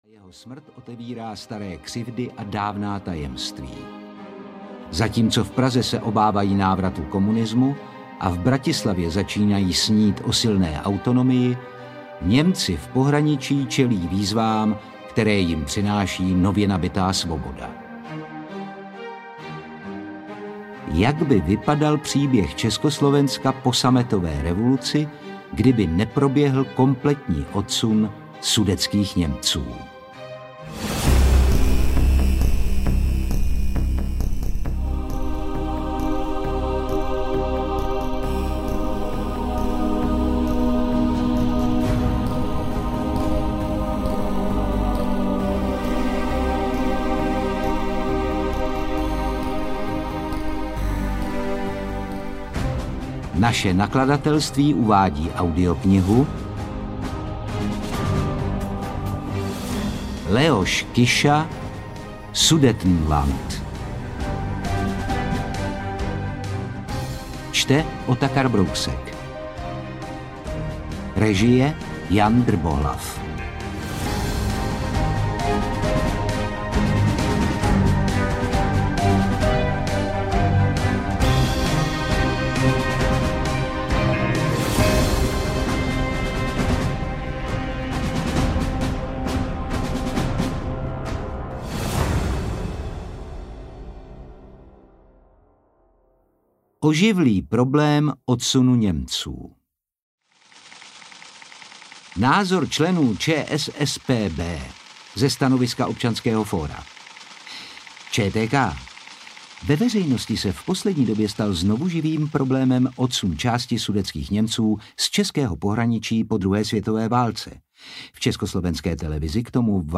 Sudetenland audiokniha
Ukázka z knihy
• InterpretOtakar Brousek ml.
sudetenland-audiokniha